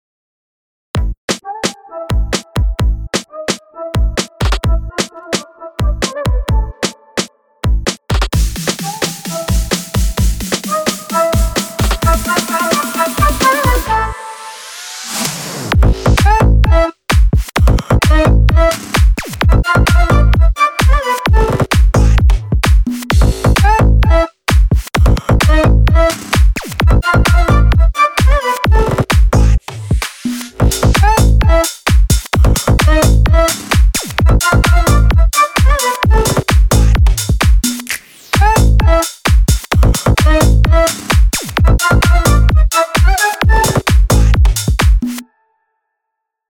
מקצבים שבניתי על קורג.
סגנוטן חדש - שילוב של סאונדים אקוסטיים…